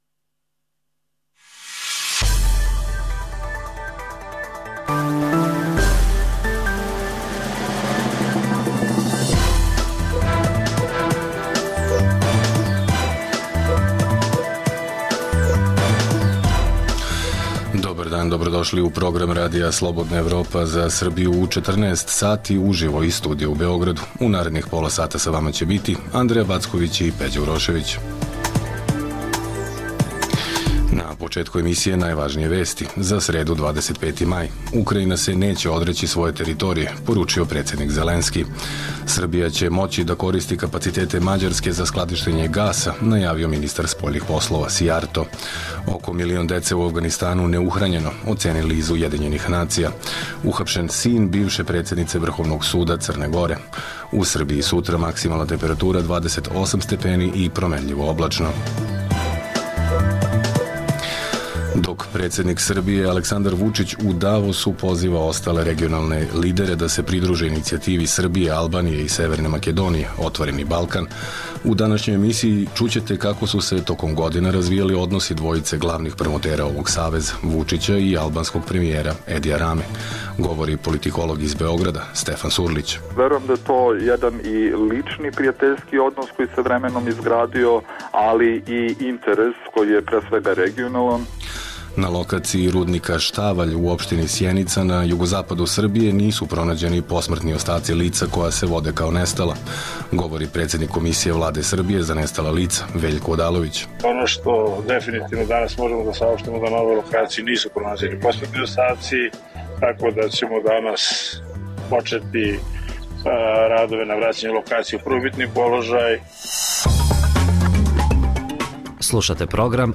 Emisija namenjena slušaocima u Srbiji. Sadrži lokalne, regionalne i vesti iz sveta, tematske priloge o aktuelnim dešavanjima iz oblasti politike, ekonomije i slično, te priče iz svakodnevnog života ljudi, kao i priloge iz sveta.